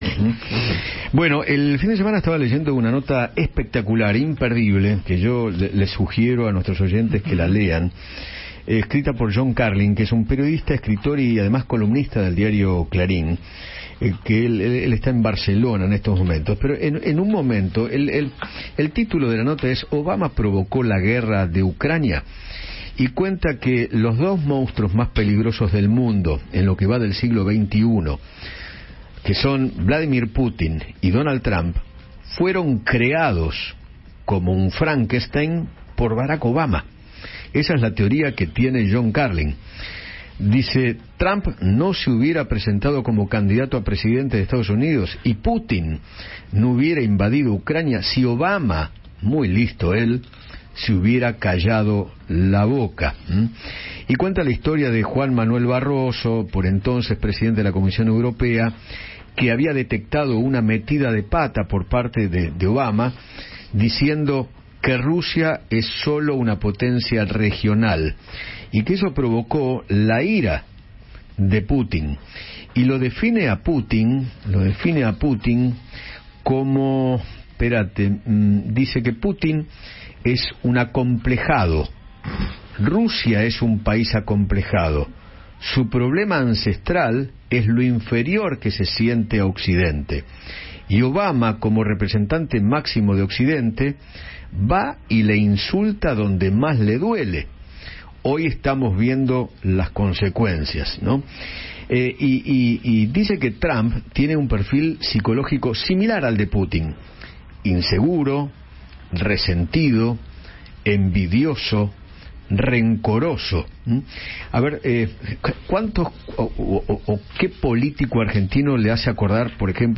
John Carlin, escritor y periodista británico del diario Clarín, habló con Eduardo Feinmann sobre la columna que escribió para aquel diario y que tituló “¿Obama provocó la guerra de Ucrania?”.